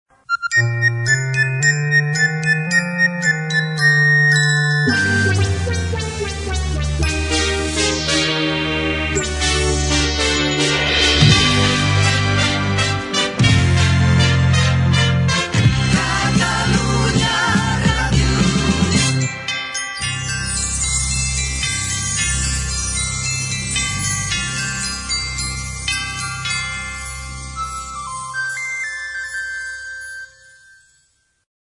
Indicatiu nadalenc de l'emissora